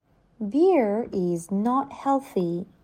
جدول کلمات، جمله ها و معنی آن به همراه تلفظ با سه سرعت مختلف:
تلفظ با سرعت‌های مختلف